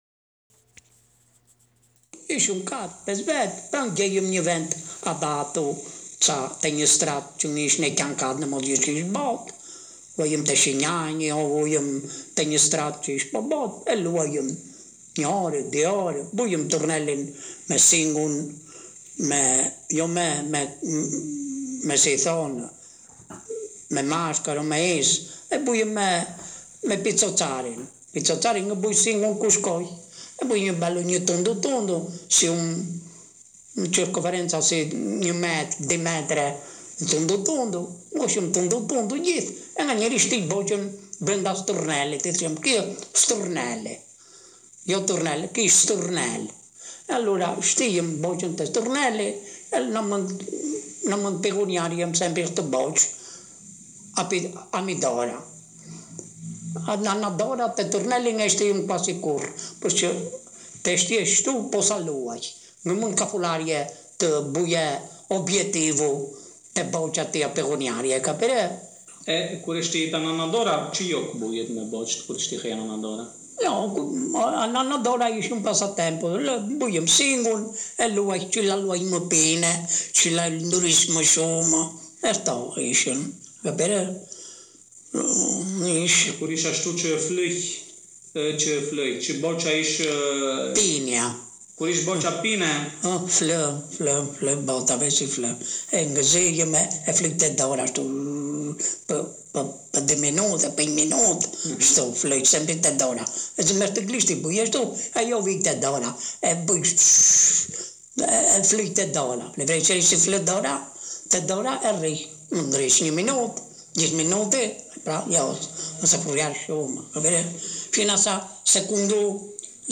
6. Etnotesti
1. Piana degli Albanesi (PA)